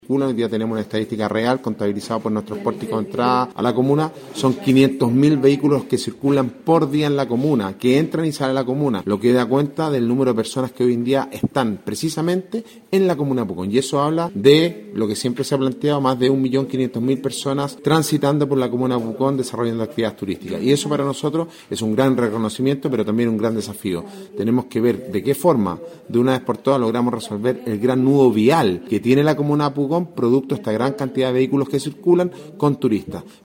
Alcalde-Sebastian-Alvarez-sobre-numero-de-vehuculos-en-la-zona.mp3